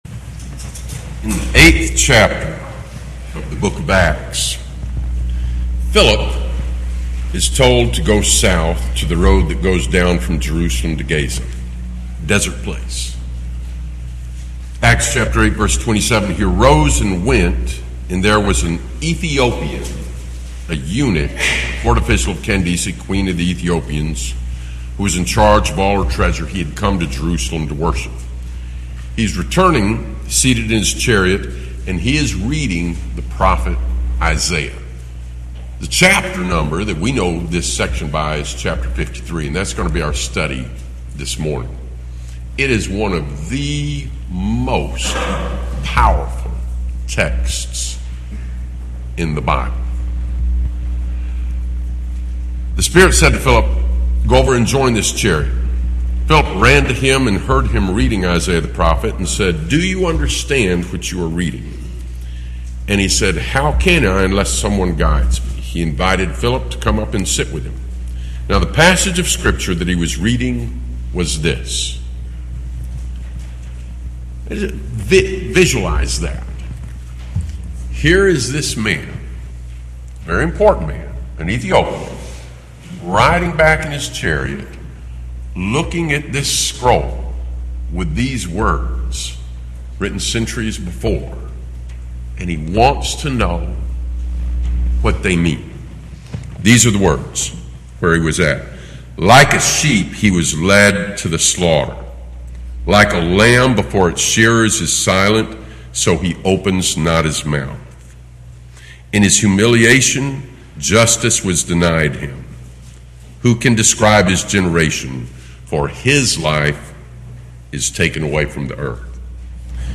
Bible Text: Isaiah 53 | Preacher